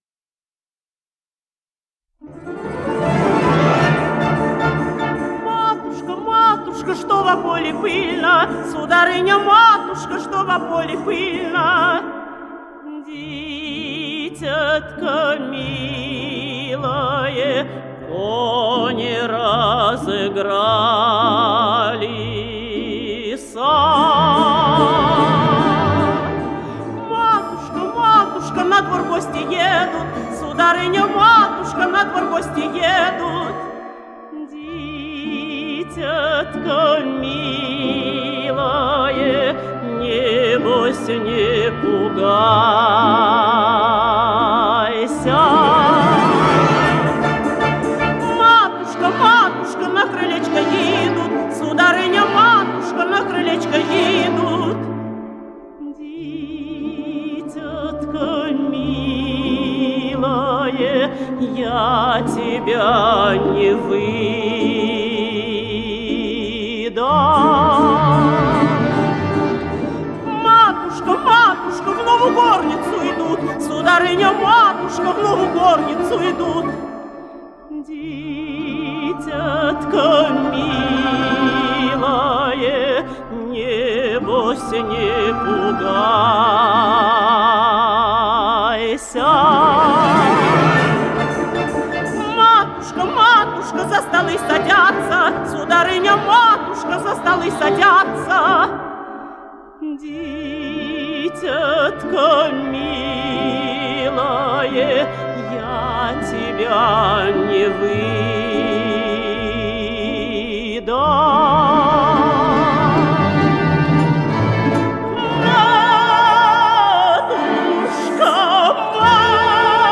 Слушание: русская народная песня «Матушка, матушка, что во поле пыльно» в исполнении народной артистки СССР Людмилы Зыкиной
Тема дочери. Тема матери взволнованное обращение дочери построено на повторяющихся нисходящих и вновь взлетающих интонациях, которые не получают завершения. Смятение, тревога, переживание, драматический накал чувств Успокаивающие ответы матери построены на неторопливой, постепенно спускающейся мелодии, которая приводит к устойчивому звучанию.
Покорность, смирение с безвыходной ситуацией Песня звучит напряжённо, в миноре.
Русская народная песня